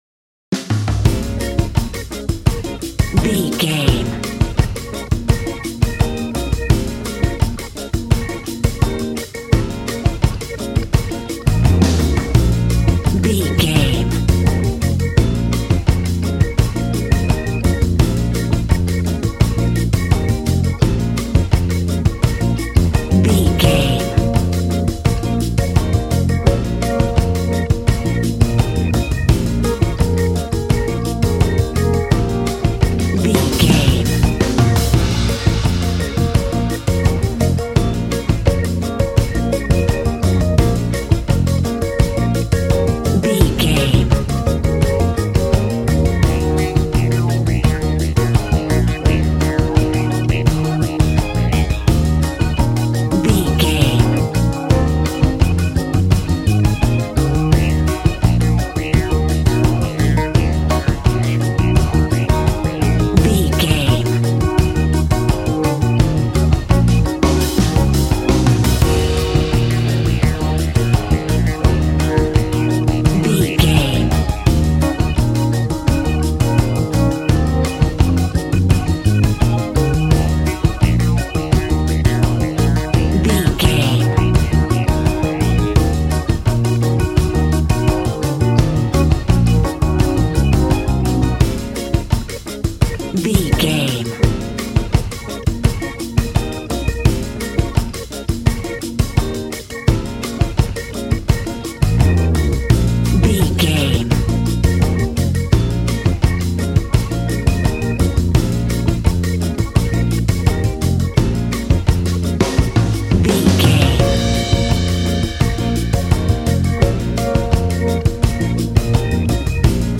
Aeolian/Minor
B♭
relaxed
smooth
synthesiser
drums
80s